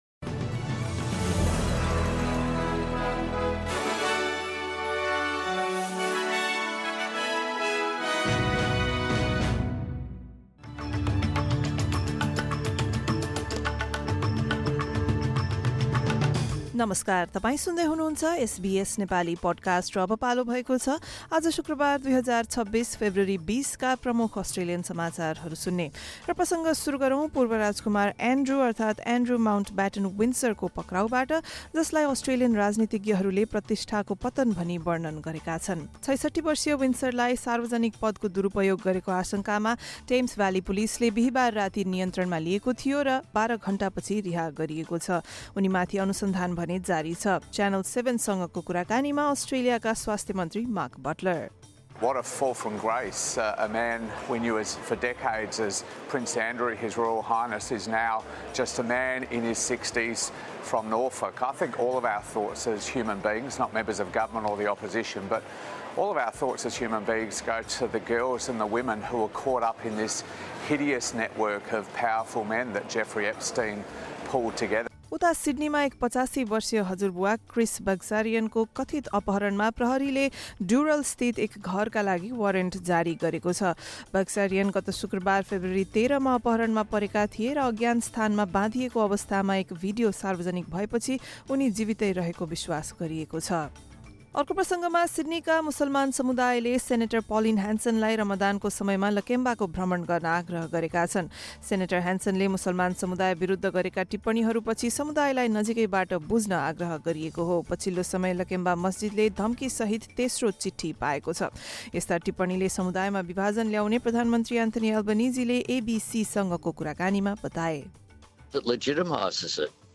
SBS Nepali Australian News Headlines: Friday, 20 February 2026